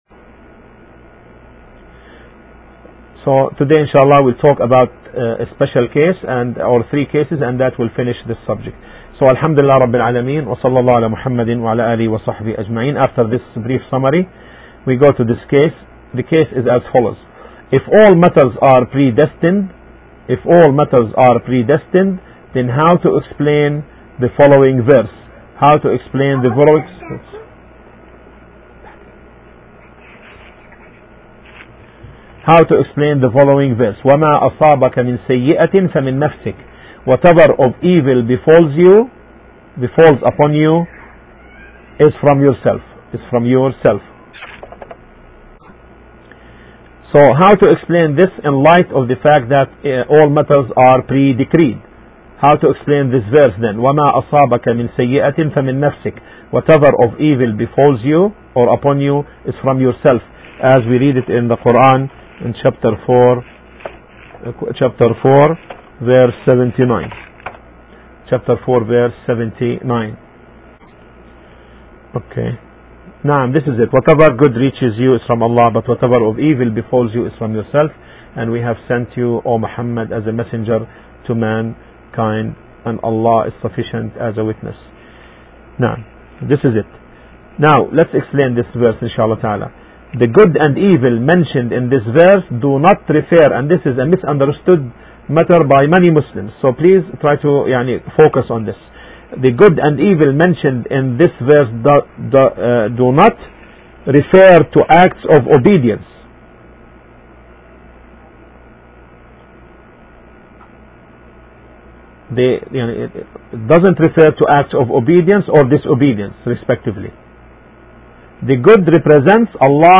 Pre-decree of Allah - Al Qadaa wal Qadar - 11 - Last Lecture